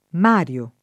m#rLo] pers. m. — pl., occorrendo, Mari (alla lat. Marii): I Cinna, i Silla, i Mari [